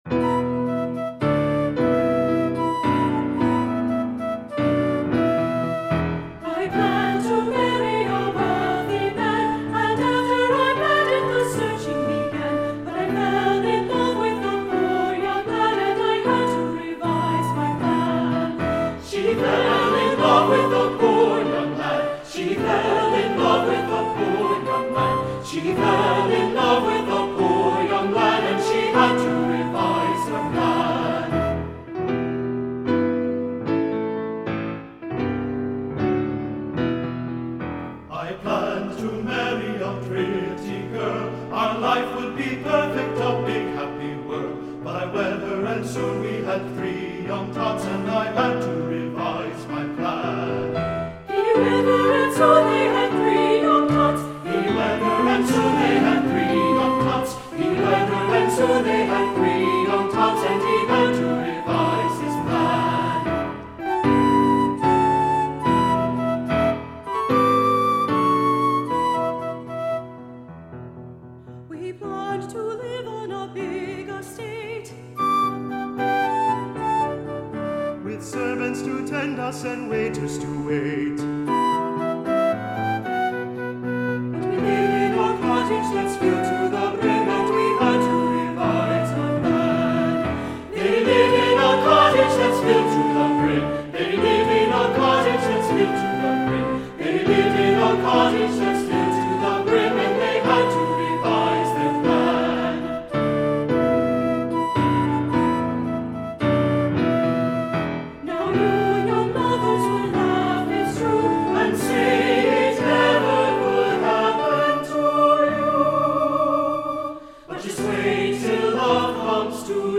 Voicing: SATB
Instrumentation: Flute and Piano